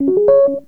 03 Rhodes 13.wav